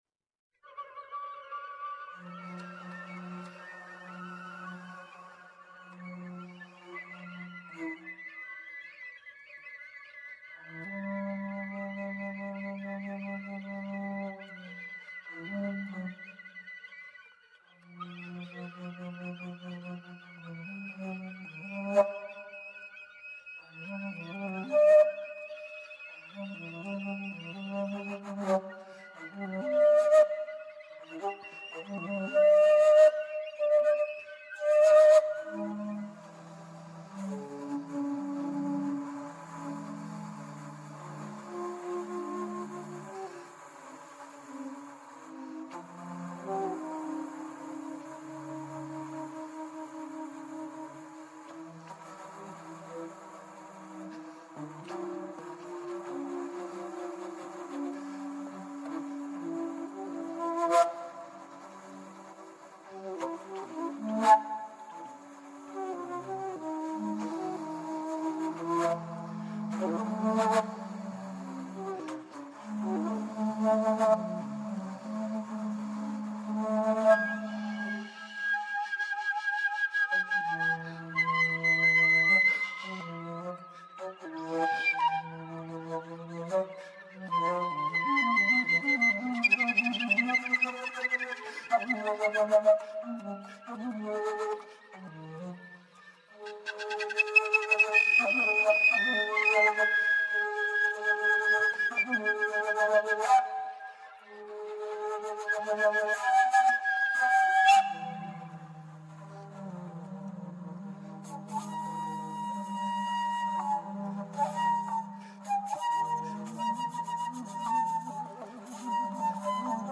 per flauto basso e 6 flauti preregistrati